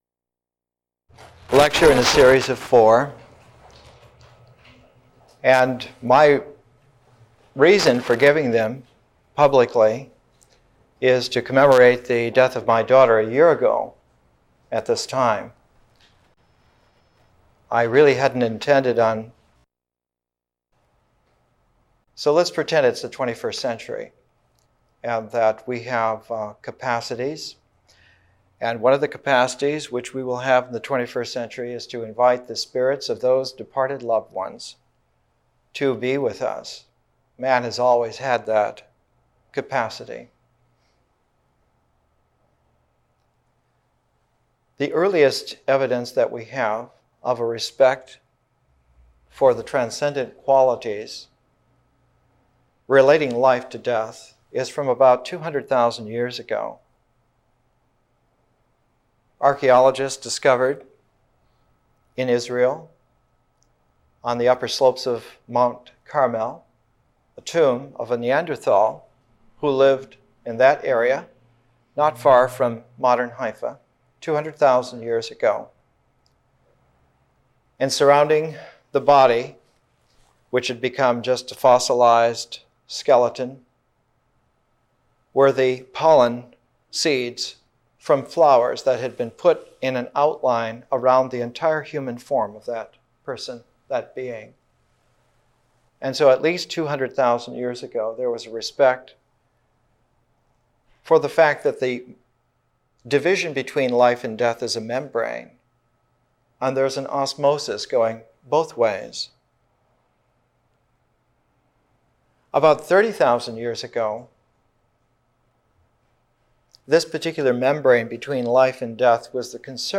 Presentations are 1-1.5 hours: an initial session of 30-45 minutes, an intermission for discussion or contemplation, and a second 30-45 minute session.